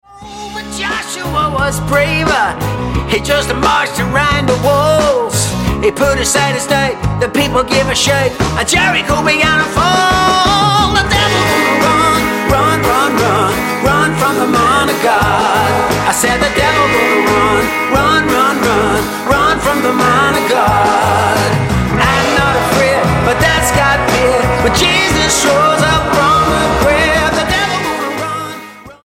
STYLE: Blues
satisfyingly raw collection of original gospel blues numbers